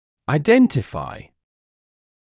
[aɪ’dentɪfaɪ] [ай’дэнтифай] индентифицировать, распозновать, определять как (identify as)